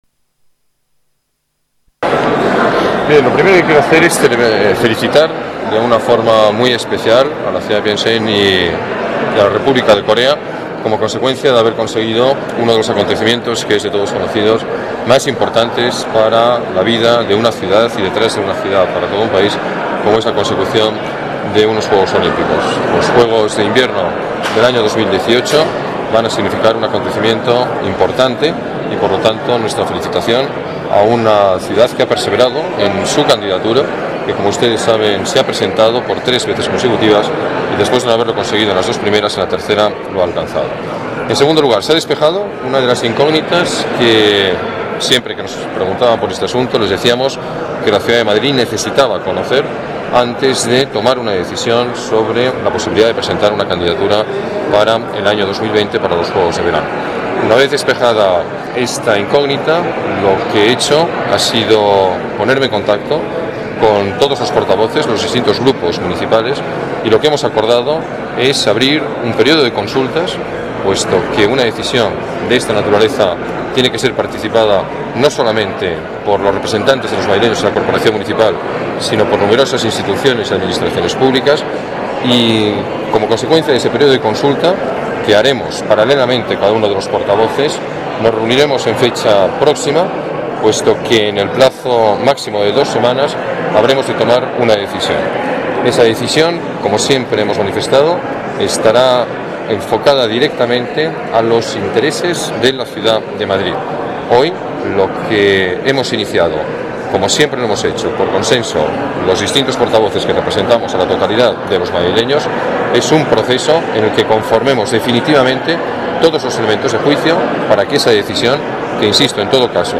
Nueva ventana:Declaraciones alcalde, Alberto Ruiz-Gallardón: decisión candidatura olímpica Madrid